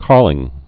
(kärlĭng, -lĭn)